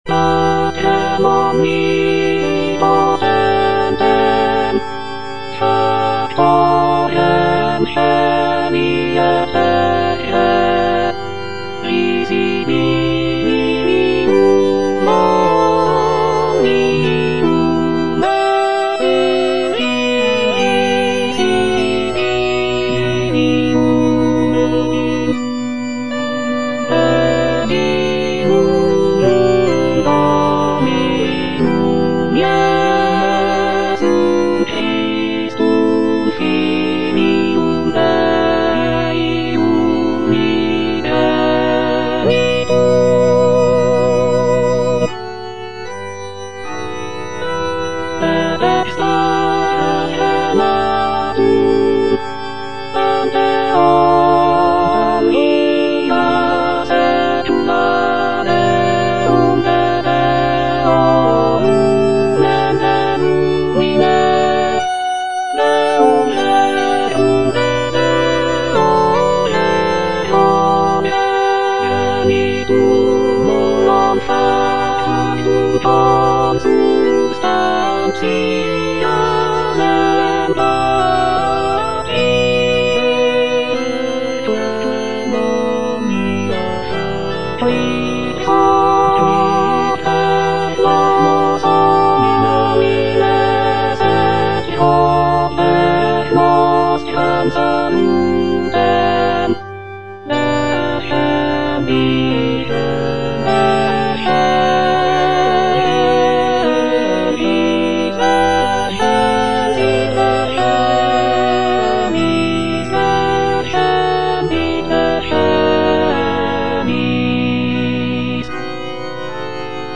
J.G. RHEINBERGER - MISSA MISERICORDIAS DOMINI OP.192 Credo - Alto (Emphasised voice and other voices) Ads stop: auto-stop